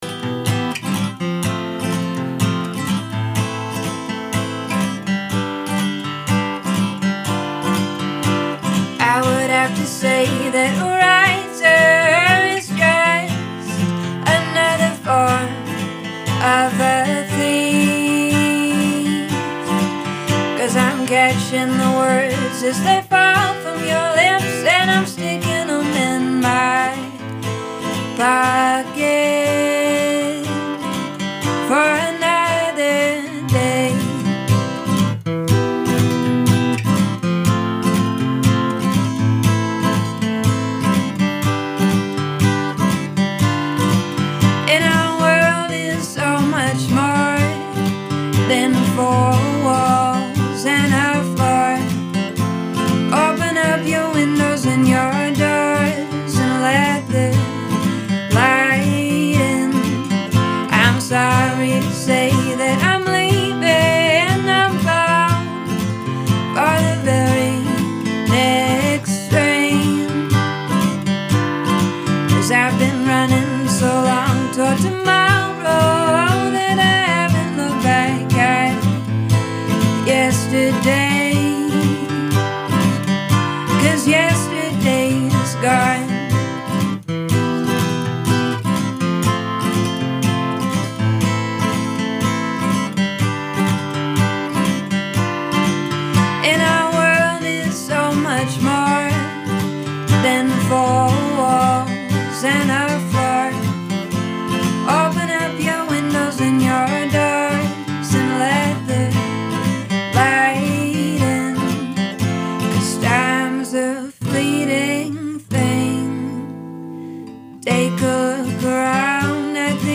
EXTRA INTERVIEW